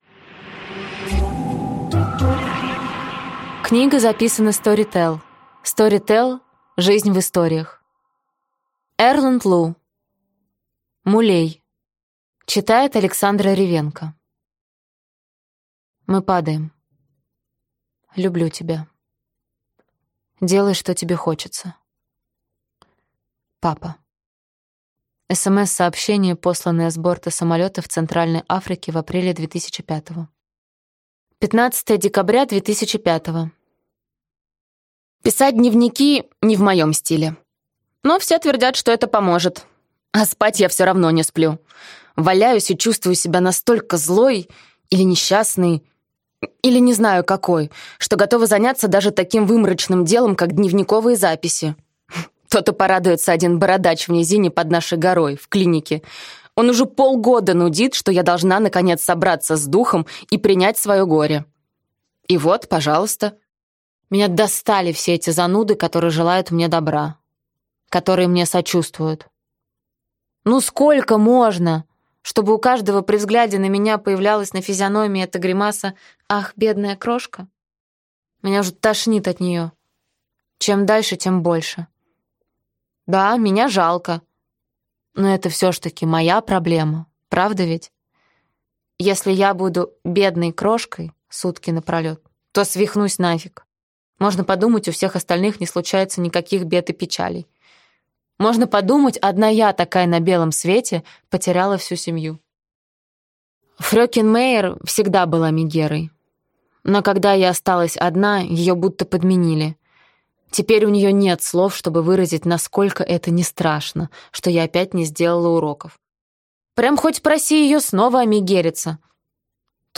Аудиокнига Мулей | Библиотека аудиокниг